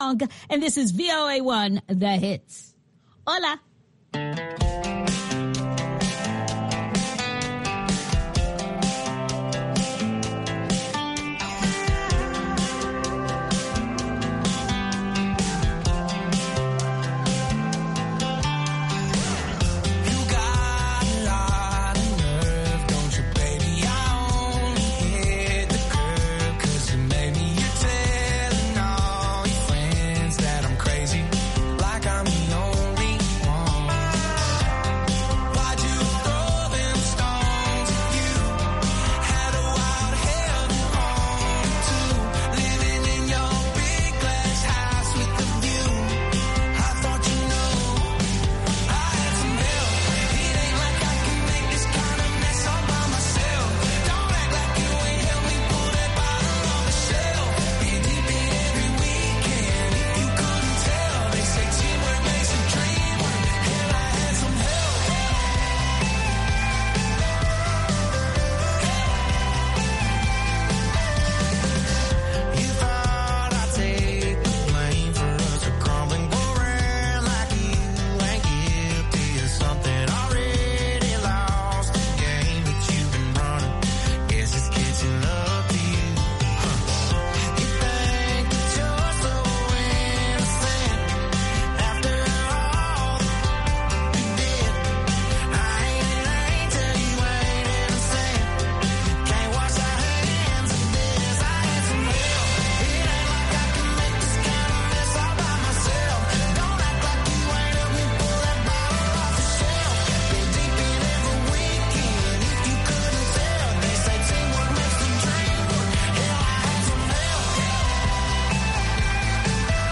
Idaacadda Duhurnimo